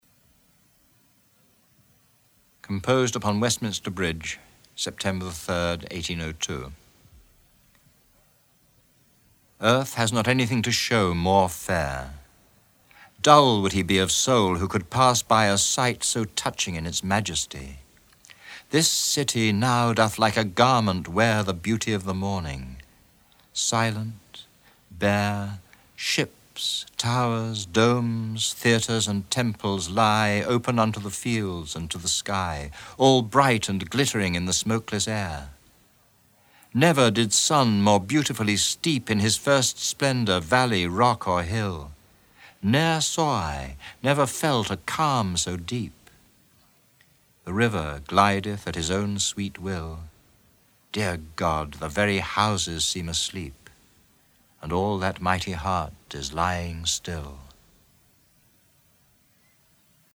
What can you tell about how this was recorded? Recordings from a selection of 72 Poems on the Underground originally recorded on tape and published as a Cassell Audiobook in 1994